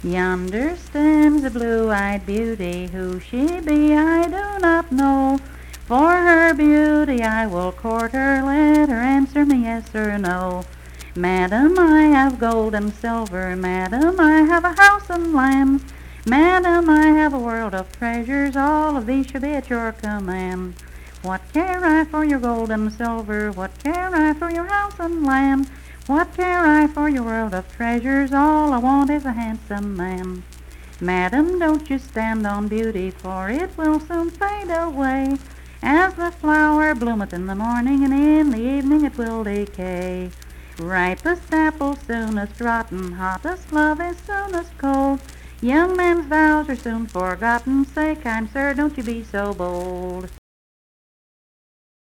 Unaccompanied vocal music performance
Marriage and Marital Relations, Dance, Game, and Party Songs
Voice (sung)
Marion County (W. Va.)